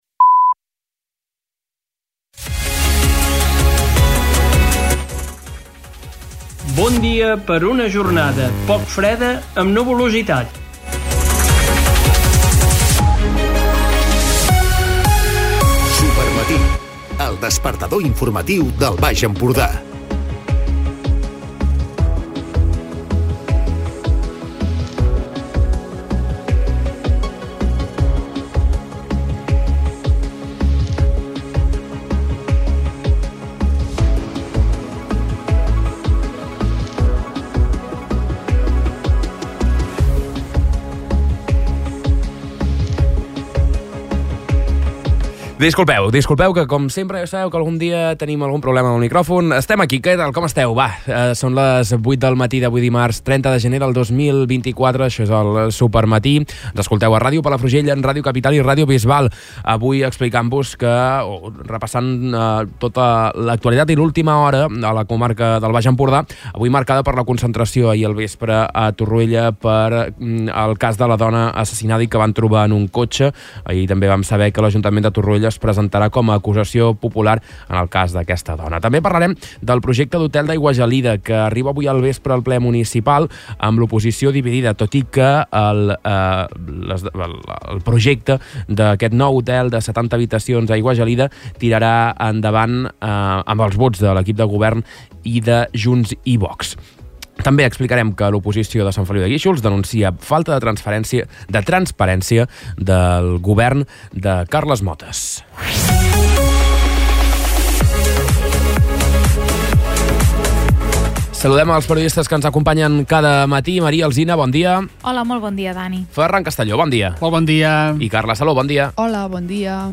Escolta l'informatiu d'aquest dimarts